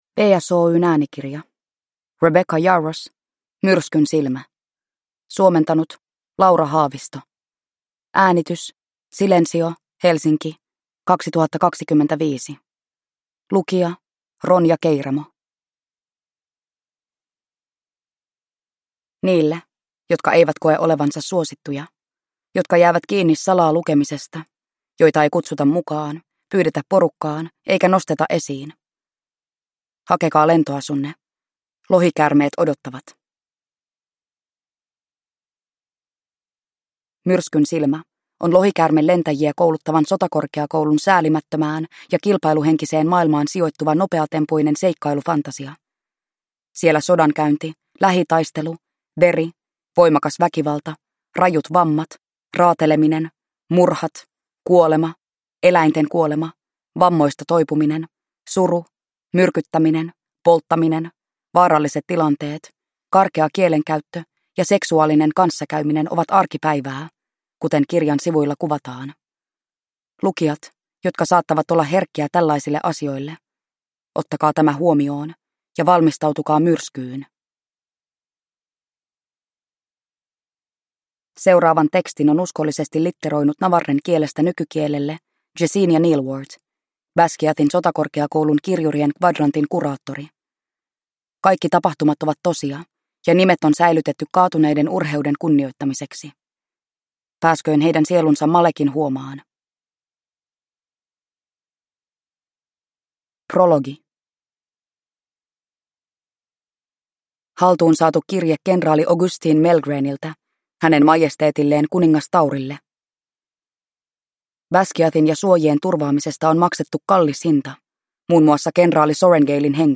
Myrskynsilmä – Ljudbok